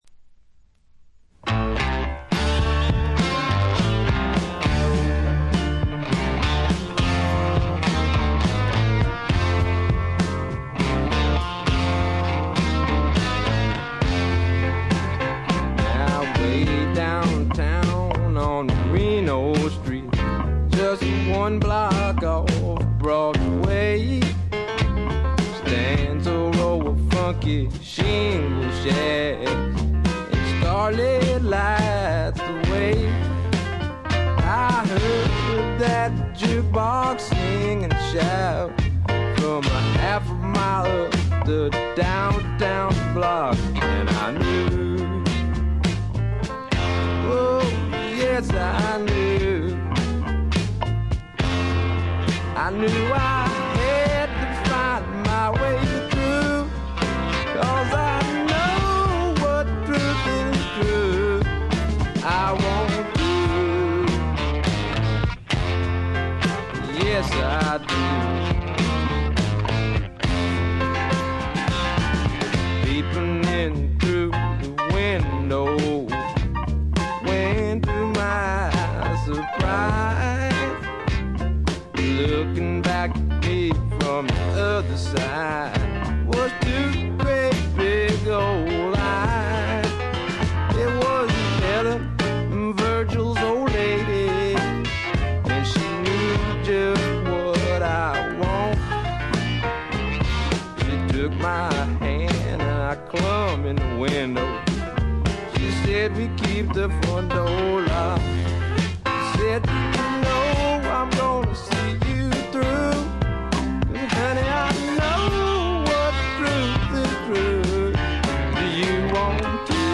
軽微なチリプチ少し。
まさしくスワンプロックの真骨頂。
試聴曲は現品からの取り込み音源です。